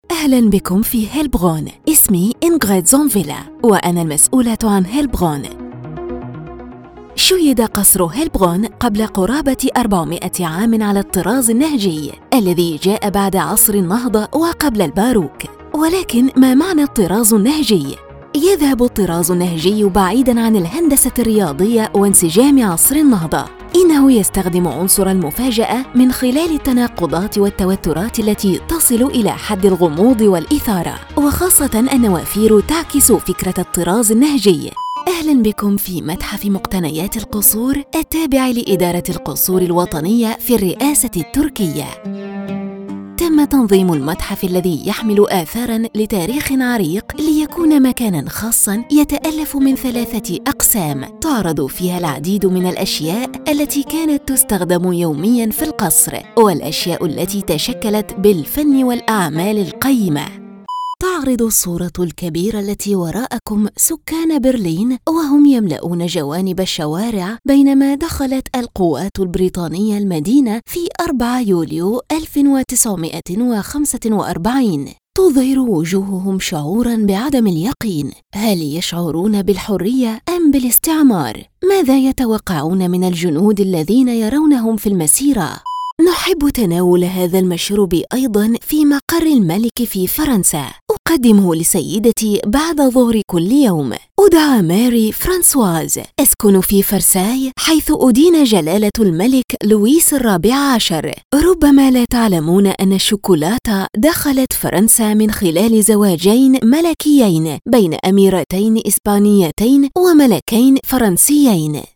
Female
Global Museums Tour Guides
All our voice actors have professional broadcast quality recording studios.
1102Museums_Arabic__Demo_Reel.mp3